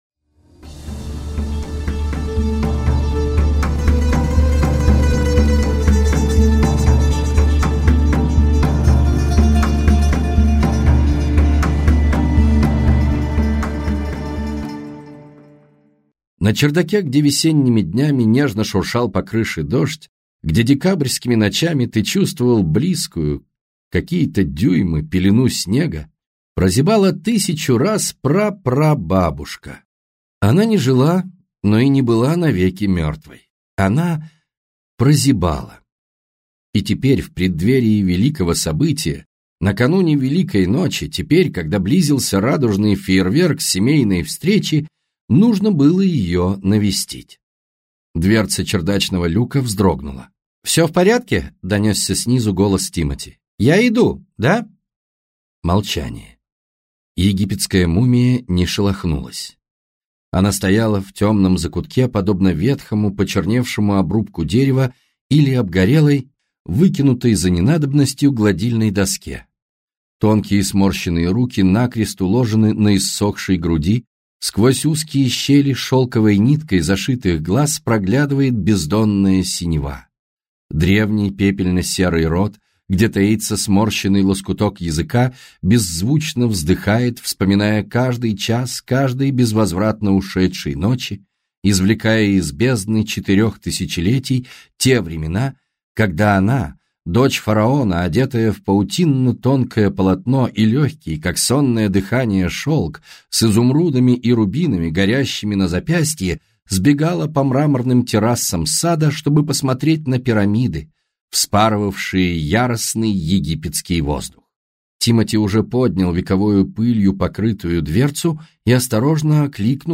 Аудиокнига Из праха восставшие - купить, скачать и слушать онлайн | КнигоПоиск